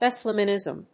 音節Wés・ley・an・ìsm 発音記号・読み方
/‐nìzm(米国英語)/